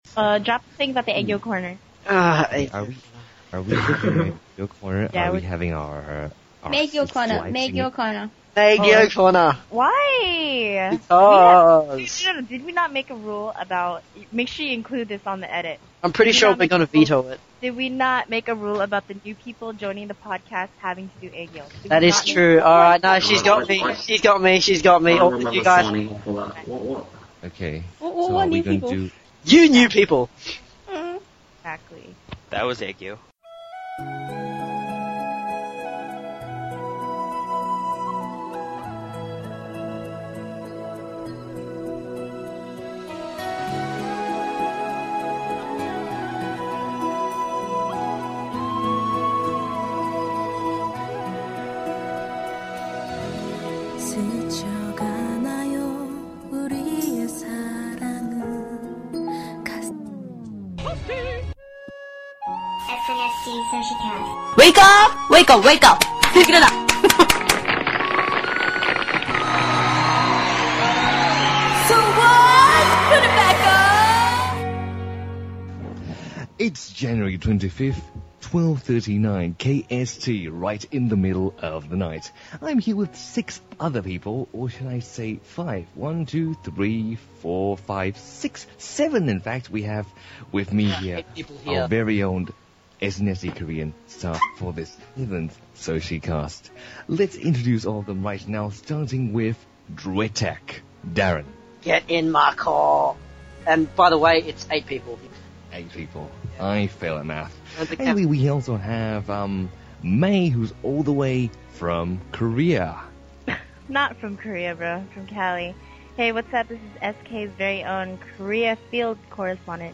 less than 24 hours after our recording session this cast is hot from the oven delivered straight to your computer.
We hit our record of most podcasters in one session capping it at 8, THAT’S RIGHT! EIGHT! PEOPLE! bask in the chaos as we argue and fight for boss lady’s love.